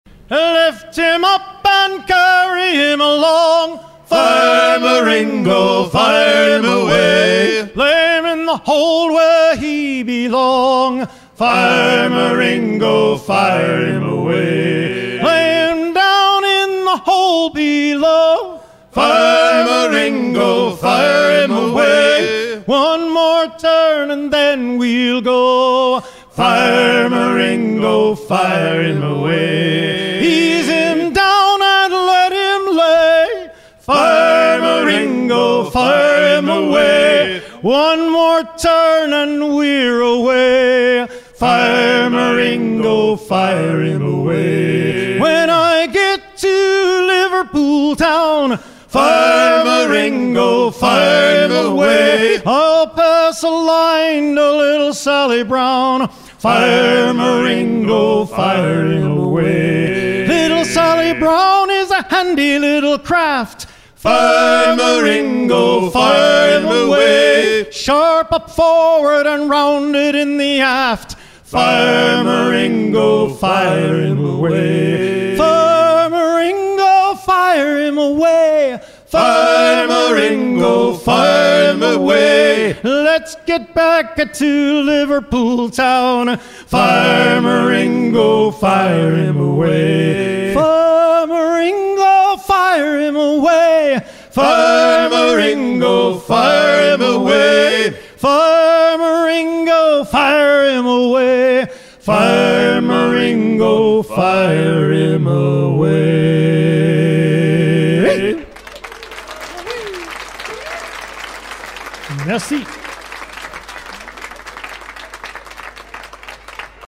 à hisser main sur main
circonstance : maritimes
en concert
Pièce musicale éditée